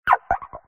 cry